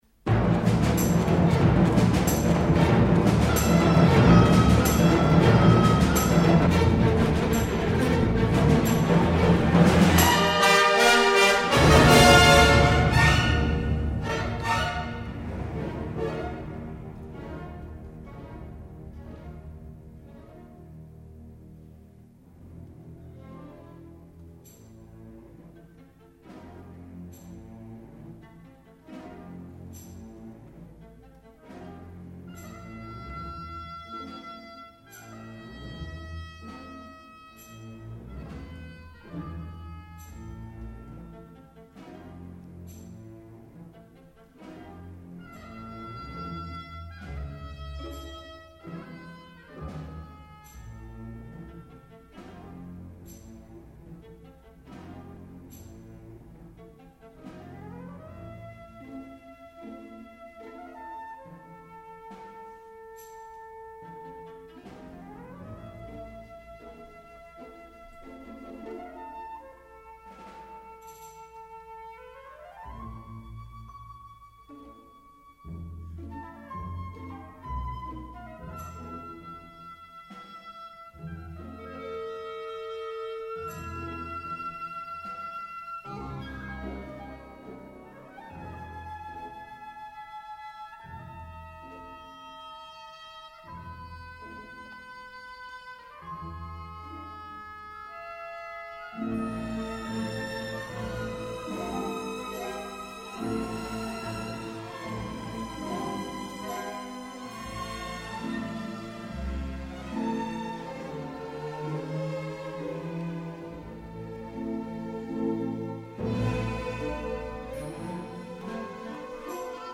莎樂美隨著熱情的曲調翩翩起舞，音樂極具官能之美，非常柔雅動聽，然後逐漸狂熱奮昂。
（五）《七紗之舞》(Dance of the Seven Veils) 是整個歌劇中最獨立的片段，表現莎樂美應了父王的要求，只要你答應了就跳舞，事實上是把七層衣紗一層一層褪下，軀身全裸，慾淫的，但帶有阿拉伯風格舞曲，樂隊寫得非常出色。
另一方面，理查 ·史特勞斯又非常符合19世紀末20世紀初的觀眾對異國情調的迷戀，寫了一個具有東方情調的舞曲音樂。